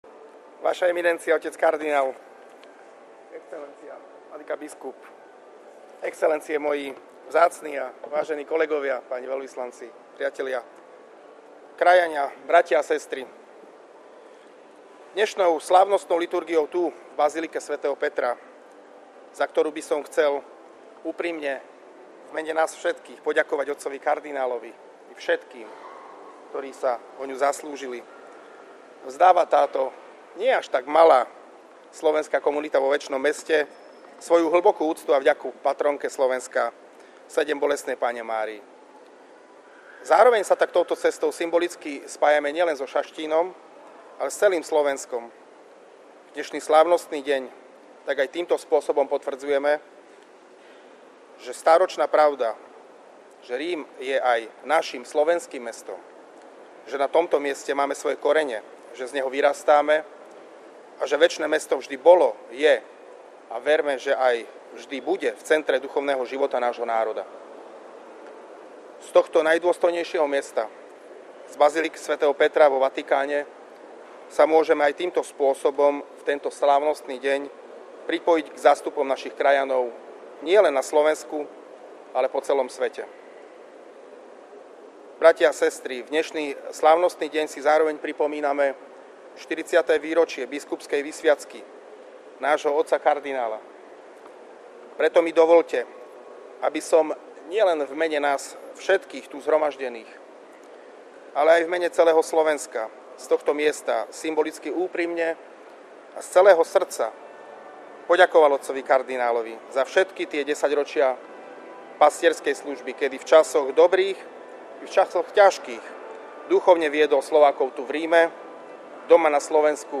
Jubilanta pri slávnosti pozdravil s blahoprajným príhovorom